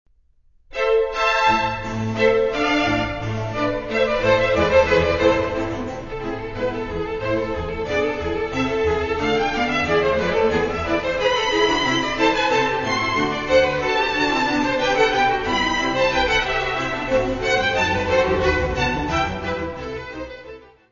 : stereo; 12 cm + folheto
Music Category/Genre:  Classical Music
(Allegro).